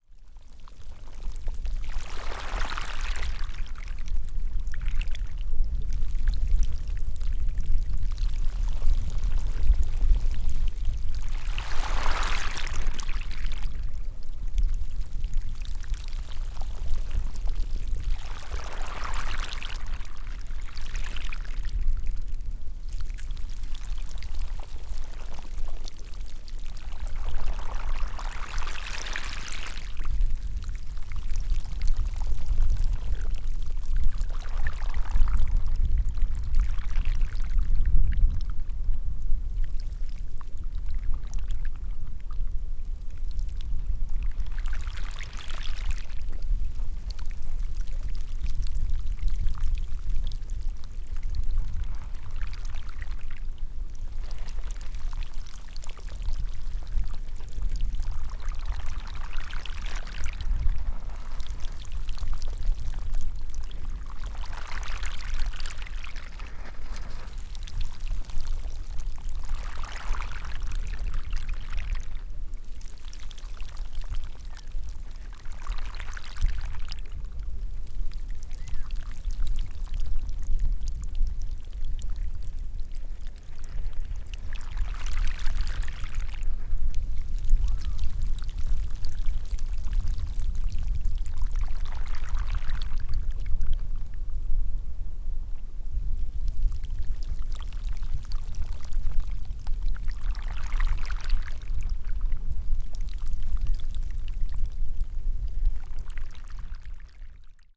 Rode NT1A in a ORTF setup.
101227, moving icy water II